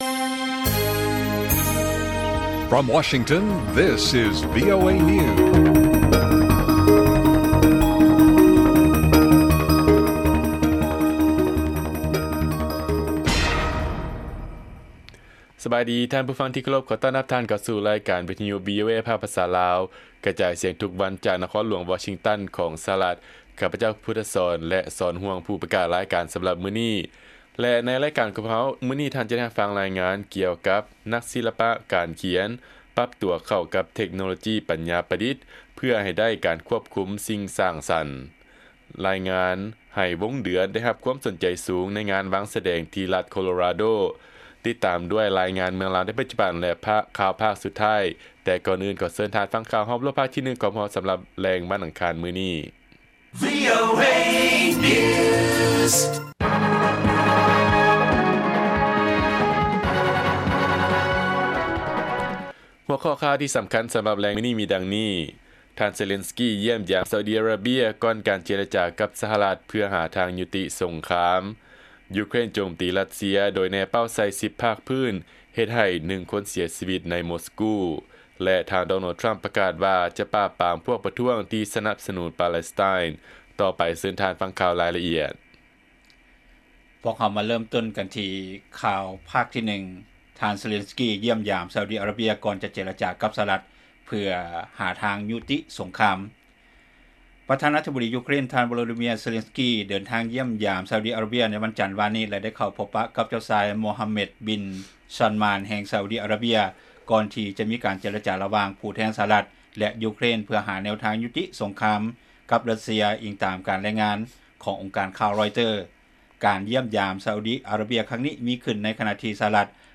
ລາຍການກະຈາຍສຽງຂອງວີໂອເອ ລາວ: ເຊເລັນສກີ ຢ້ຽມຢາມຊາອຸດິອາຣາເບຍ ກ່ອນເຈລະຈາກັບສະຫະລັດ ເພື່ອຫາທາງຍຸຕິສົງຄາມ